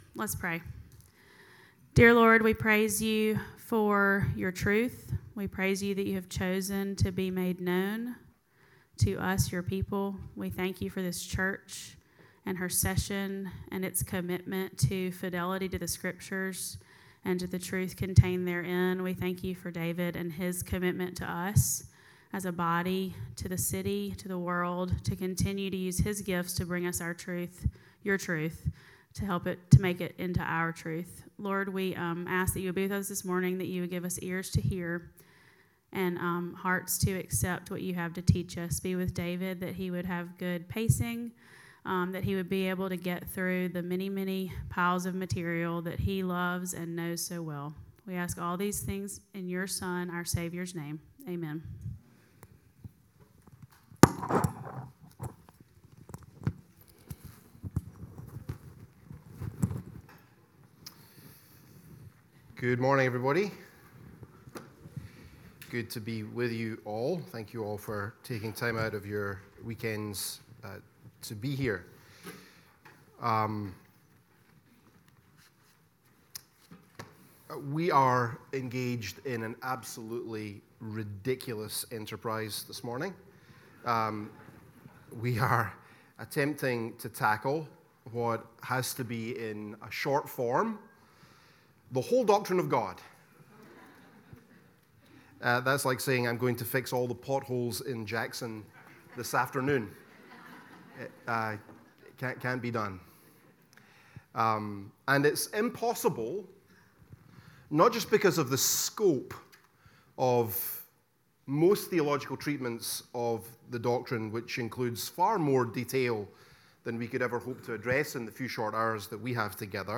The-Doctrine-of-God-Lecture-1-The-Knowability-and-Names-of-God.mp3